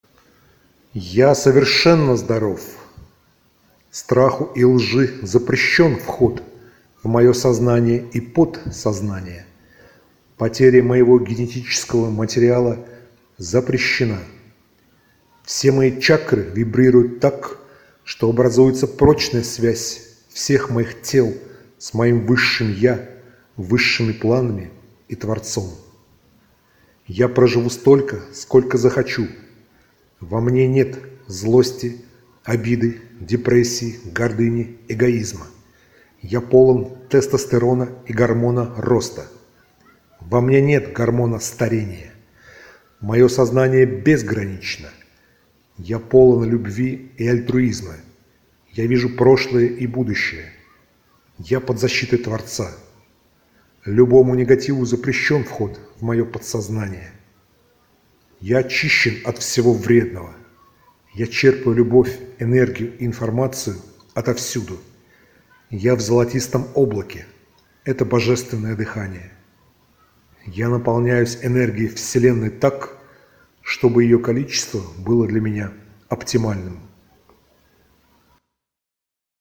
neizvesten-mantra.mp3